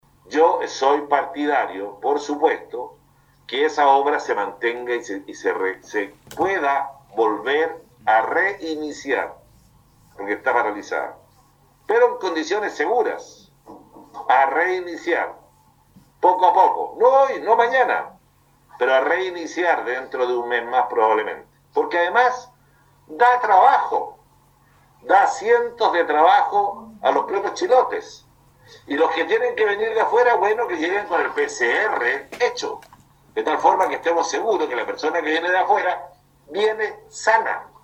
Sobre la medida que instruyó el ministro de Salud Enrique Paris, de mantener la actual suspensión de los trabajos de construcción del nuevo Hospital de Ancud, se refirió el intendente de Los Lagos.